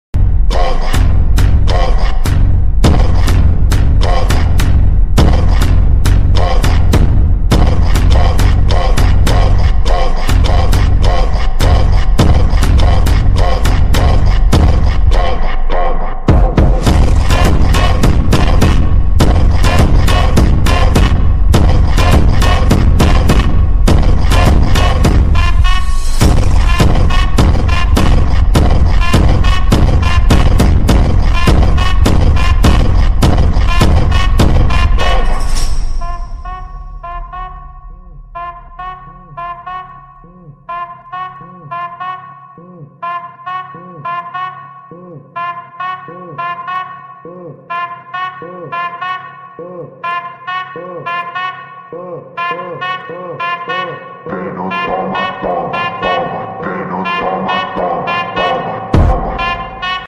Bassboster